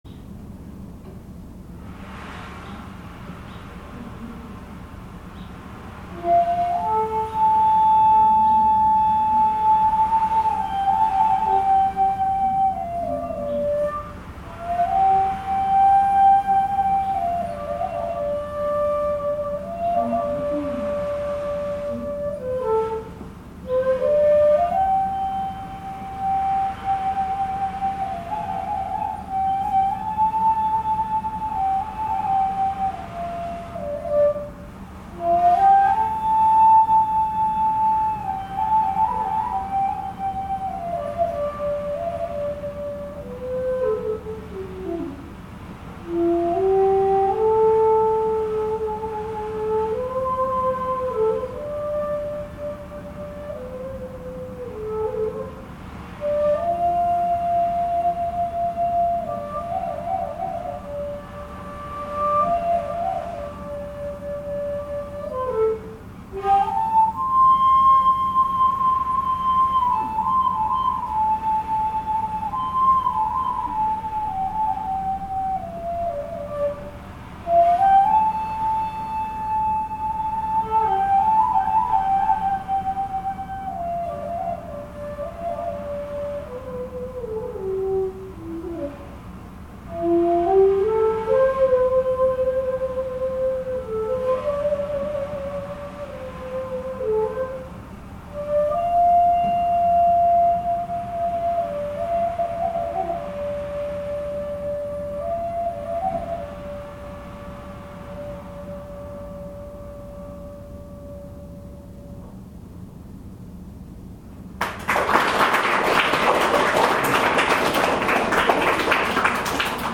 2年ぶりの小学校、琴･尺八鑑賞会
厳しい冬の北海道の荒波を想像してもらいながら「江差追分」を地無し管で吹きました。
楽器の持つ音色の力でしょうか、びっくりするほど子供達は「尺八の響きの世界」に浸っていました。